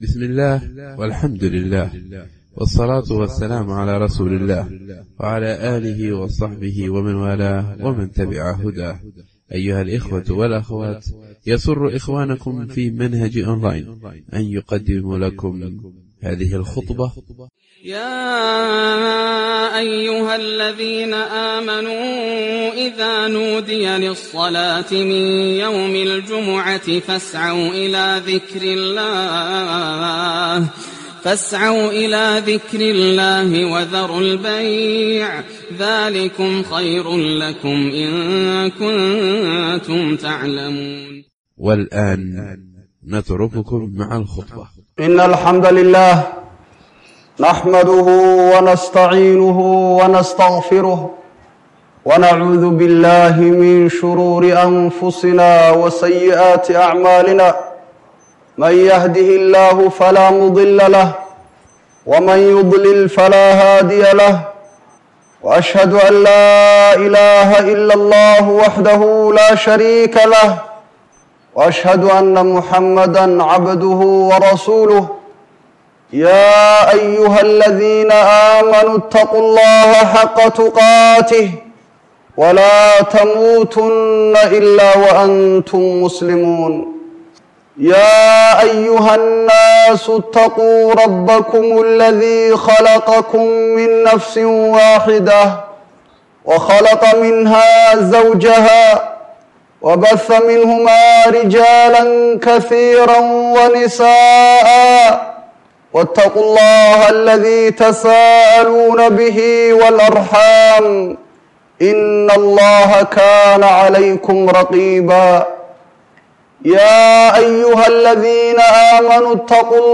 Khudbada Jimcaha